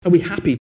In England, this frequently weakens to no more than a little ə, just like the indefinite article a. (Other accents, including General American, can weaken are without losing its r sound.) Here are native utterances by British speakers in which are is merely a schwa: